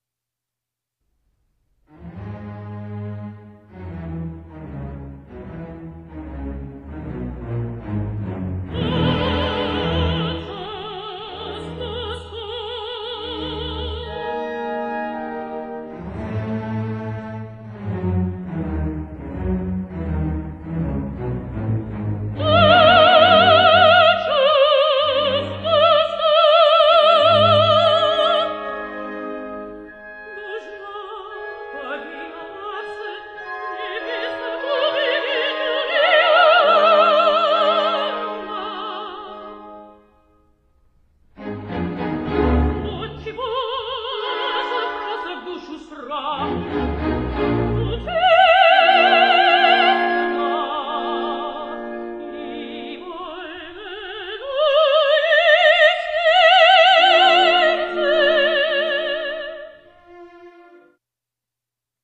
متسو سوپرانوی دراماتیک (Dramatic mezzo)
: اکثرا صدایی پایین تر از lyric mezzo و پرتر از آن دارد.
Dramaticmezzo.mp3